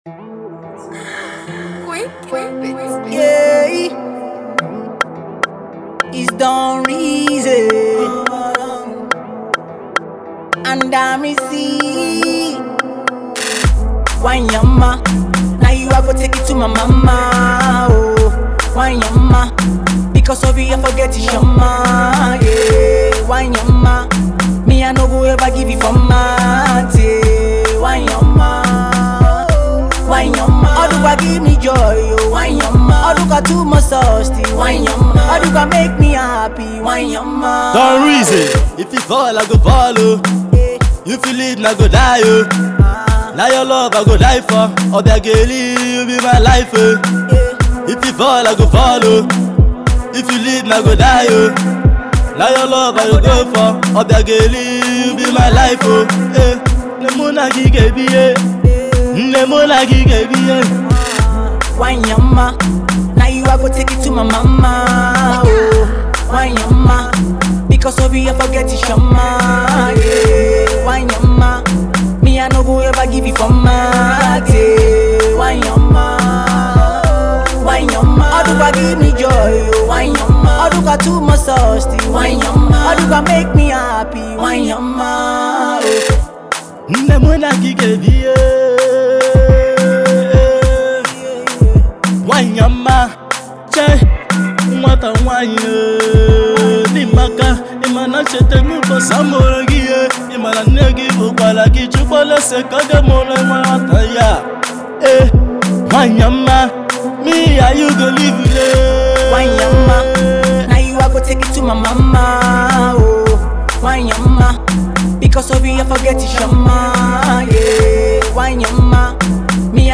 a fusion of Afro with house blend.